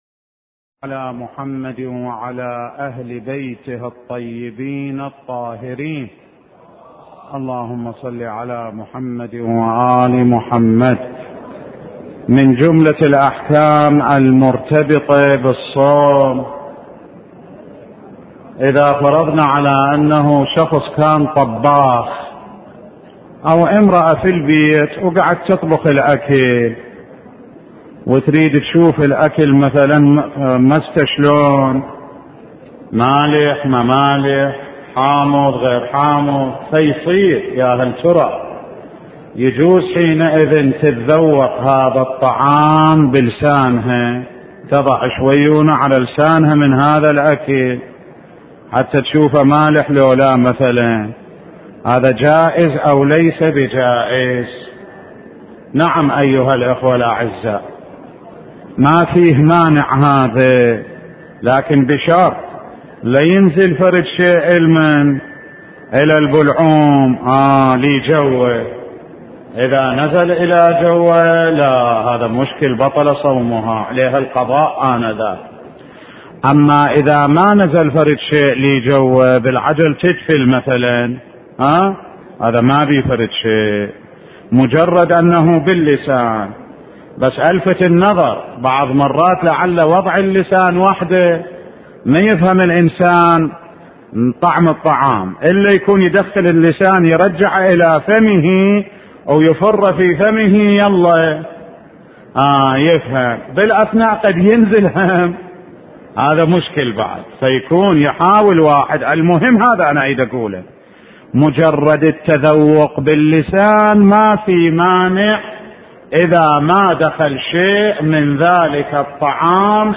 سلسلة دروس في نهج البلاغة (6) – خطبة 183 من نهج البلاغة